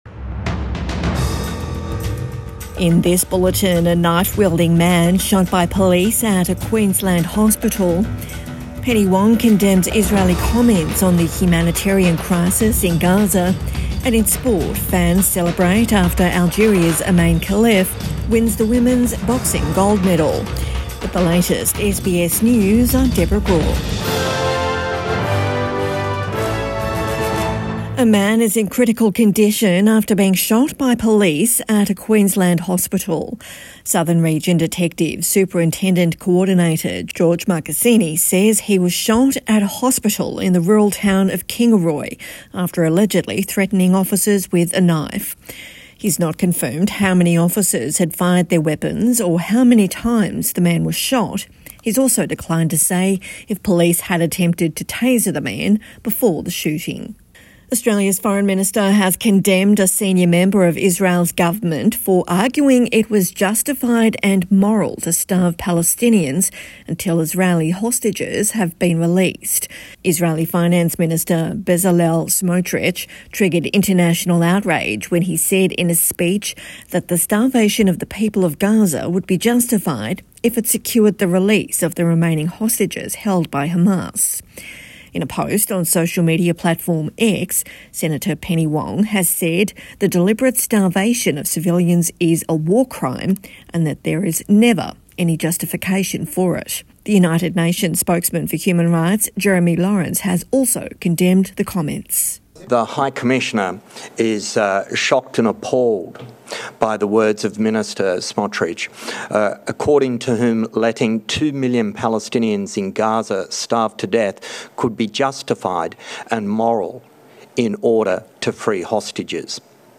Evening News Bulletin 10 August 2024